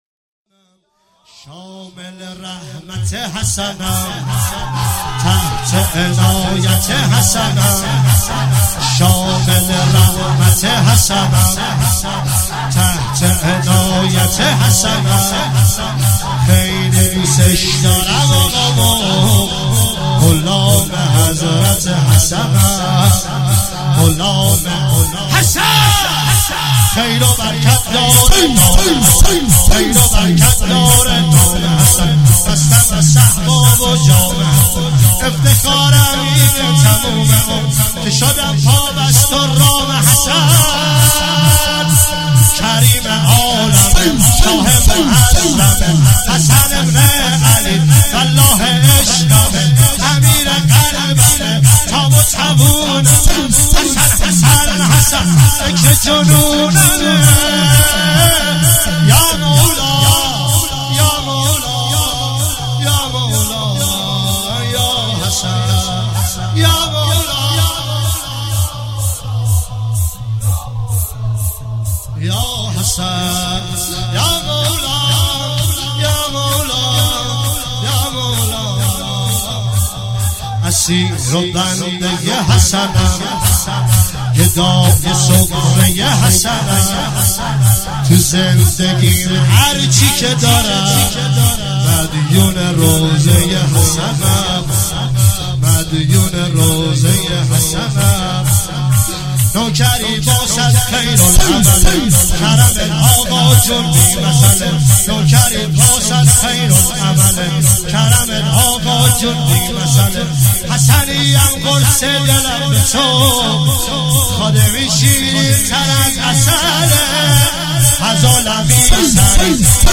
عنوان استقبال از ماه مبارک رمضان ۱۳۹۸
شور